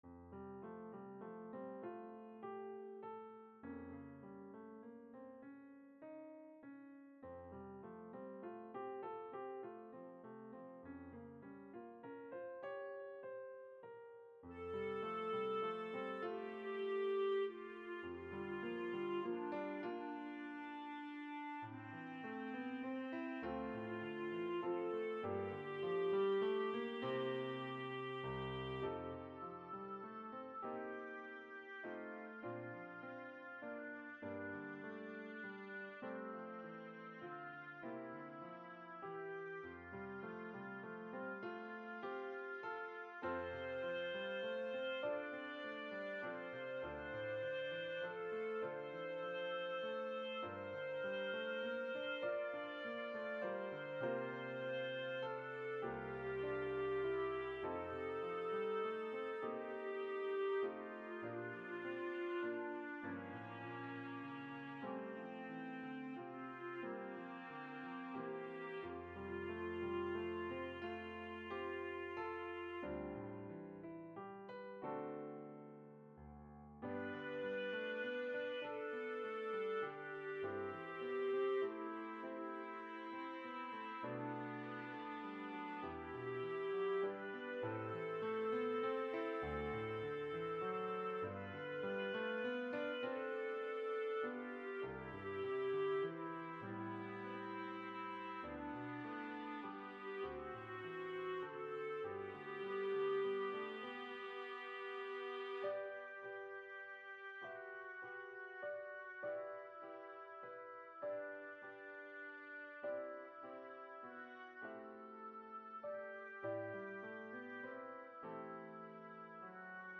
Duet, SA
This final arrangement has been through a few changes and ends up being a duet.